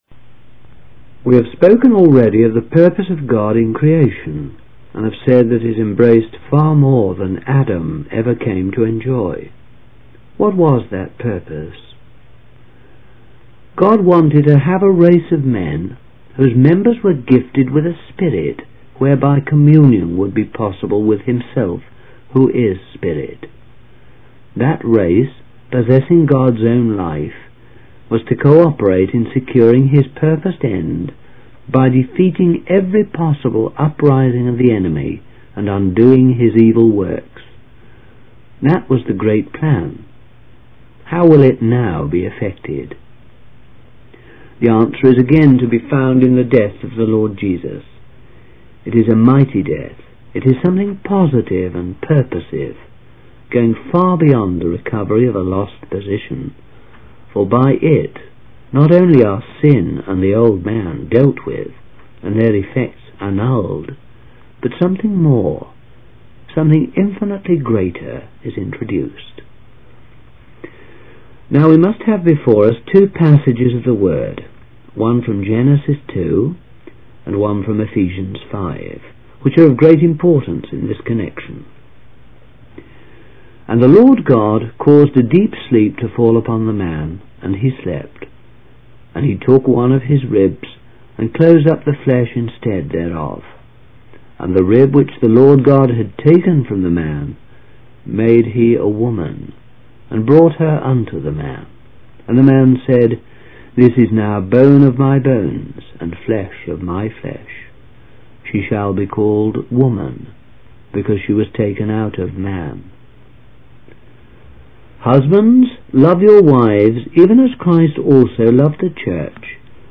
In this sermon, the preacher discusses the purpose of God in creation, which was to have a race of men who could commune with Him through the gift of the spirit.